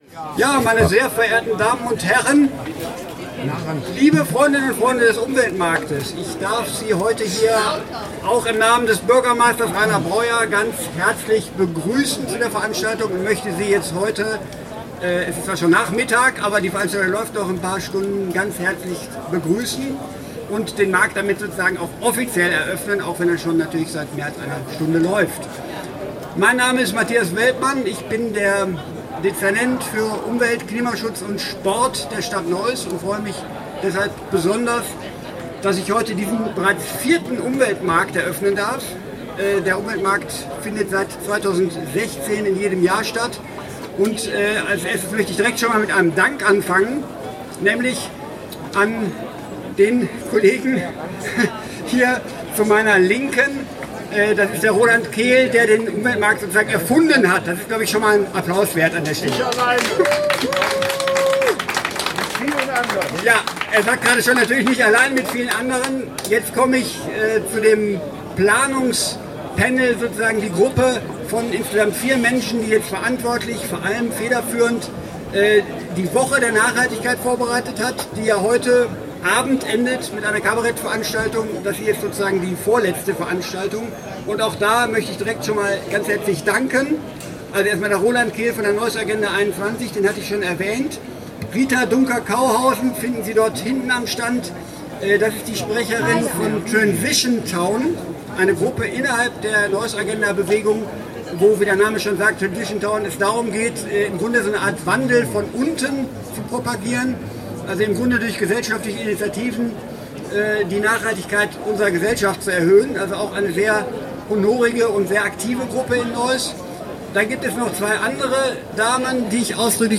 Begrüßungs- und Eröffnungsansprache von Matthias Welpmann (Audio 2/2) [MP3]